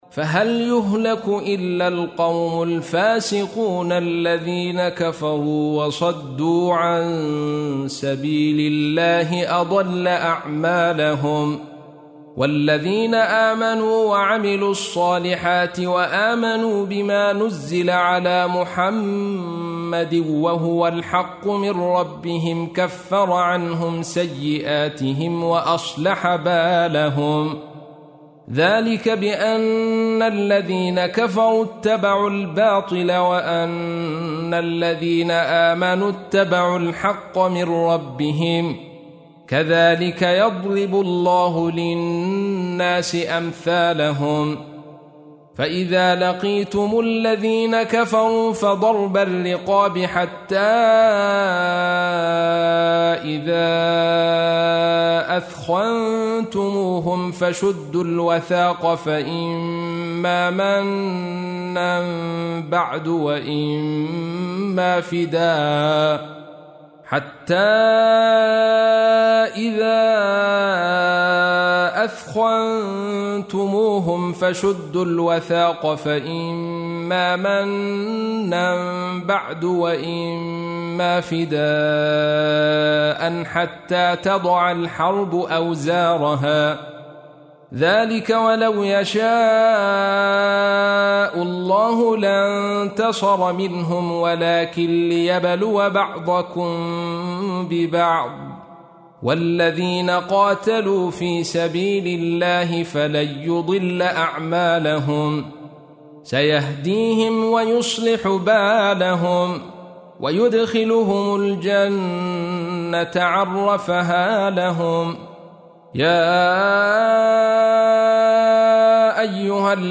تحميل : 47. سورة محمد / القارئ عبد الرشيد صوفي / القرآن الكريم / موقع يا حسين